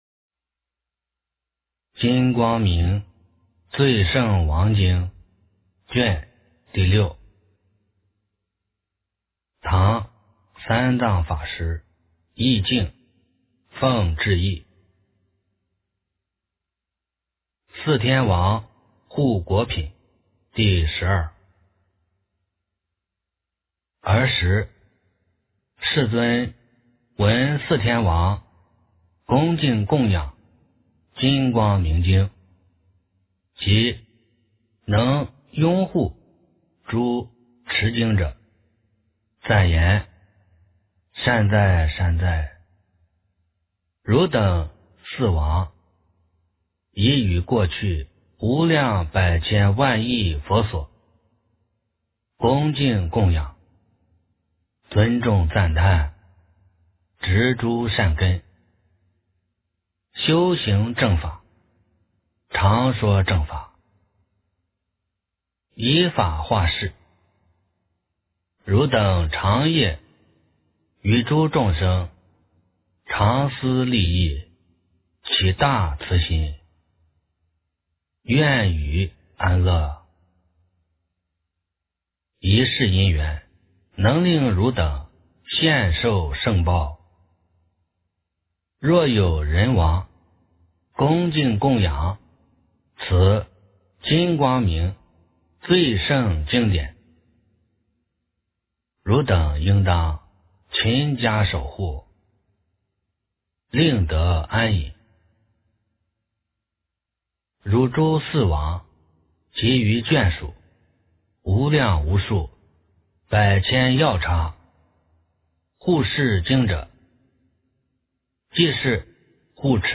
金光明最胜王经6 - 诵经 - 云佛论坛